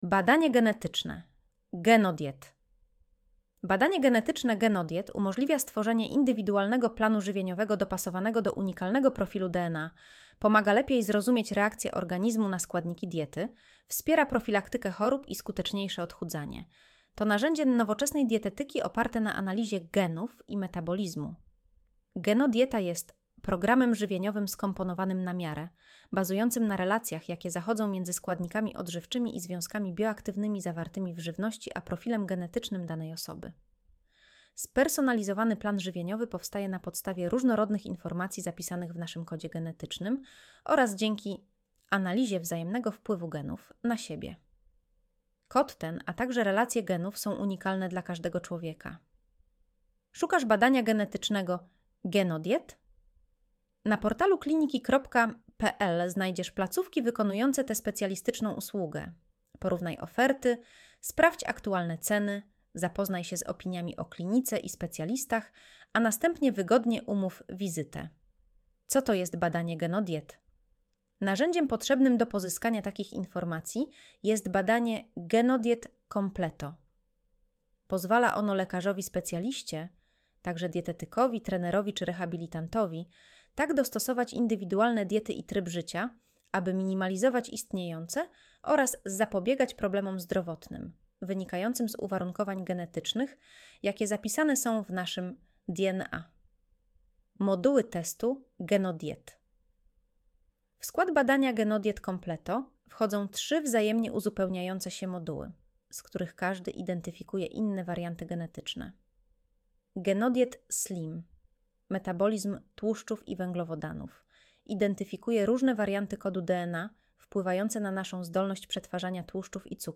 Posłuchaj (07:25 min) Streść artykuł Słuchaj artykułu Audio wygenerowane przez AI, może zawierać błędy 00:00 / 0:00 Streszczenie artykułu (AI): Streszczenie wygenerowane przez AI, może zawierać błędy Spis treści Co to jest badanie Genodiet?